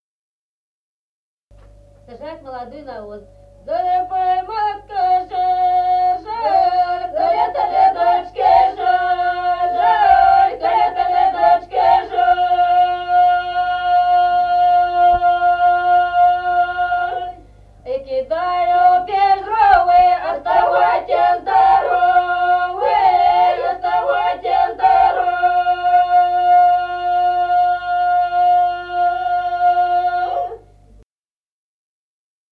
Народные песни Стародубского района «Загребай, матка, жар», свадебная.
(запев)
(подголосник)
1953 г., с. Остроглядово.